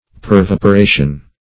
pervaporation - definition of pervaporation - synonyms, pronunciation, spelling from Free Dictionary